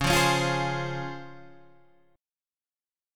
DbM7 chord